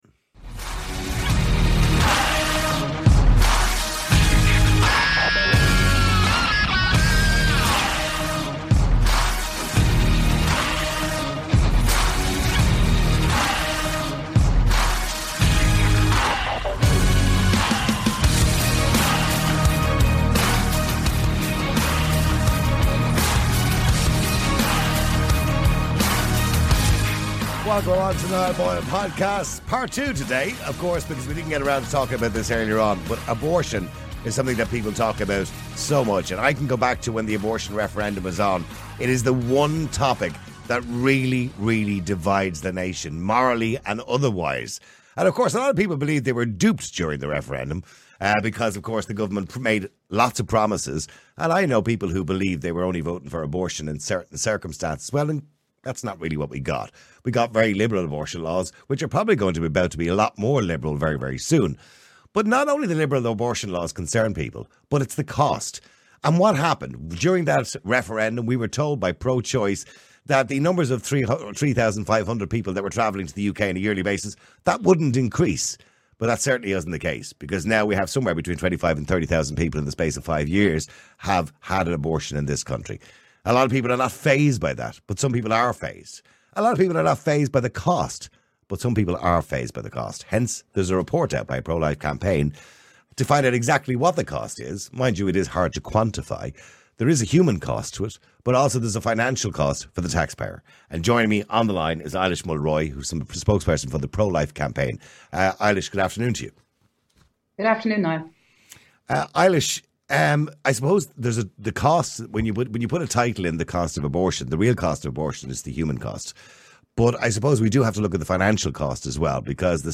In this thought-provoking interview